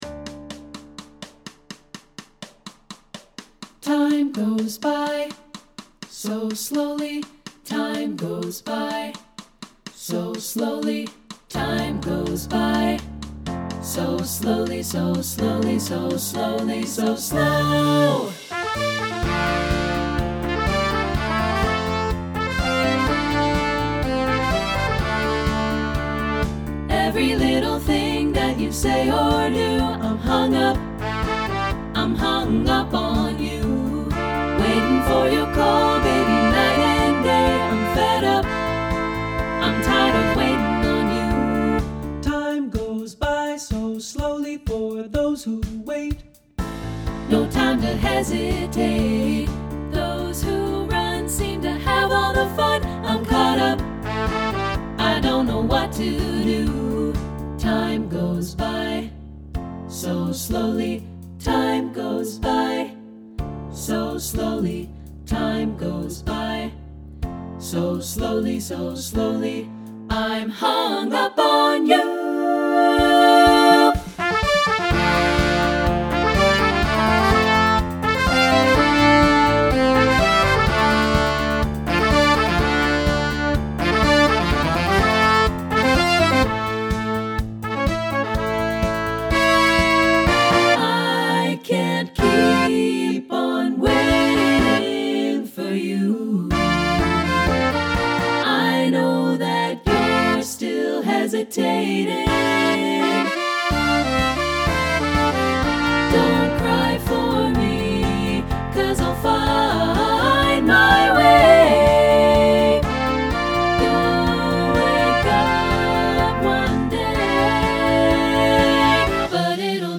contemporary choral SATB arrangement
Check out the studio demo (MIDI instruments + live voices):